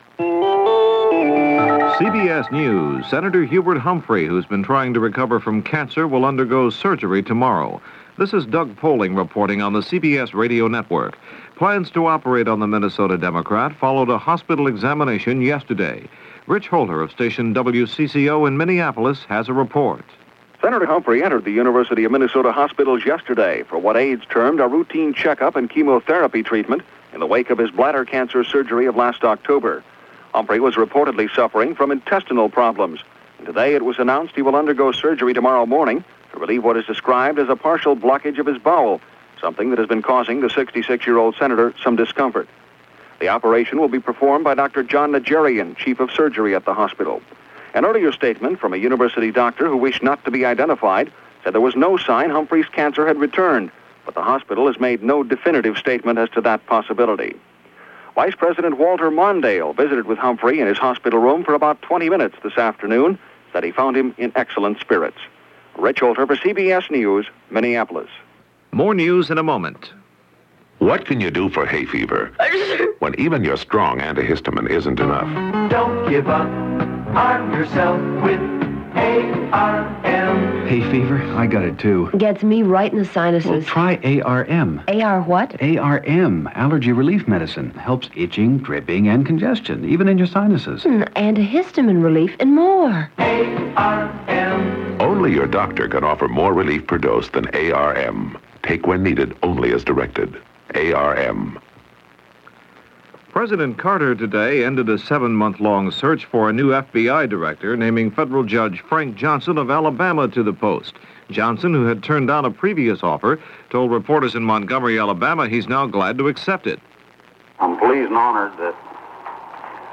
August 17, 1977 – CBS News – The World Tonight – Gordon Skene Sound Collection –